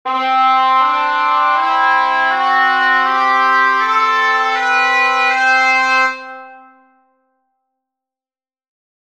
For something a bit closer to 12ET, a 7-limit interval can be used for F# instead, going 7/6 below the A instead of 6/5:
For the curious, I used Musescore2 to create these scale samples, due to it’s easy-to-apply tuning adjustments on notes, with the bagpipe sound specifically because it did not have vibrato.